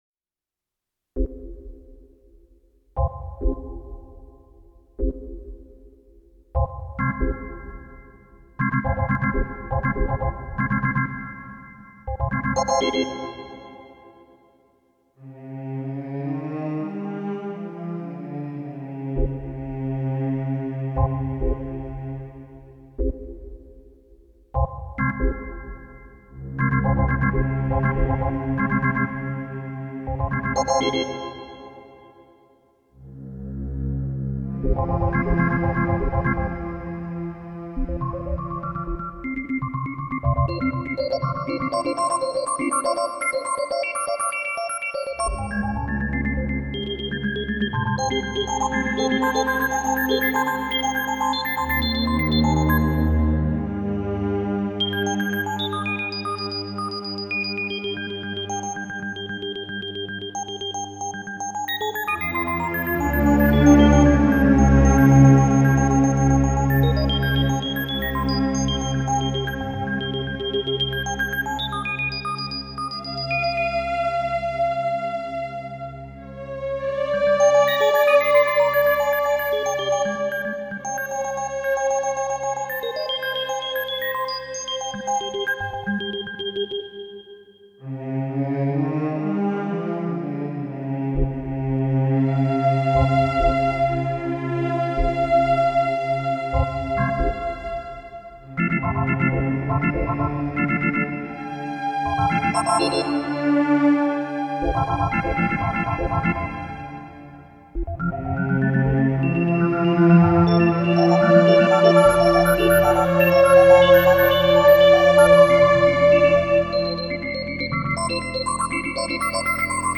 THE GOLDEN RATIO TUNING